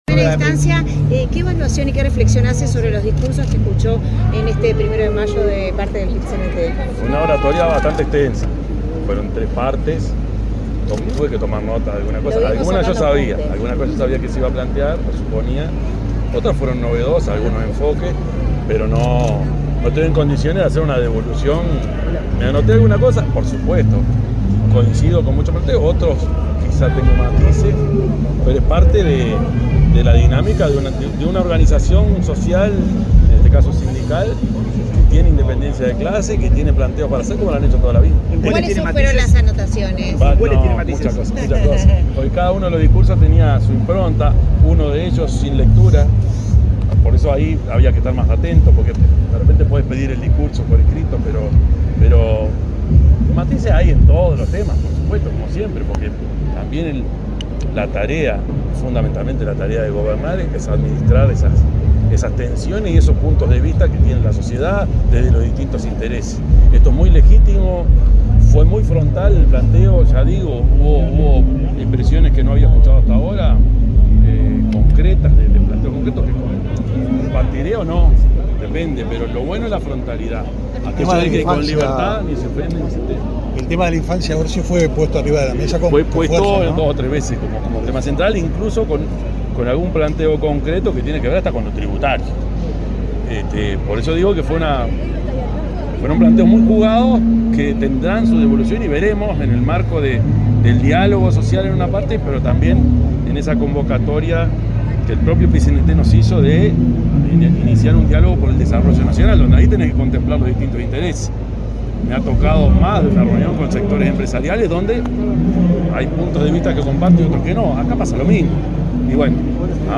Declaraciones del presidente de la República, Yamandú Orsi
Declaraciones del presidente de la República, Yamandú Orsi 01/05/2025 Compartir Facebook X Copiar enlace WhatsApp LinkedIn El presidente de la República, Yamandú Orsi, dialogó con la prensa, luego de participar en el acto convocado por el PIT-CNT en conmemoración del Día Internacional de los Trabajadores.